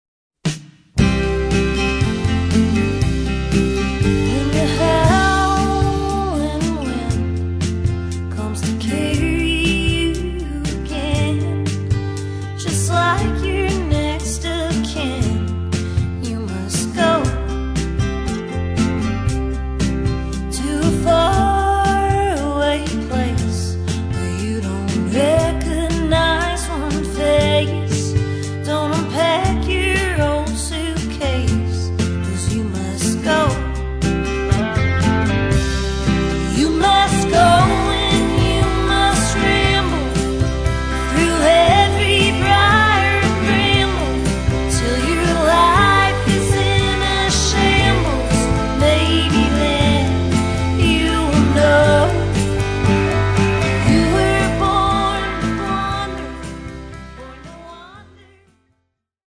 ends with a nice, unexpected ambient guitar fadeout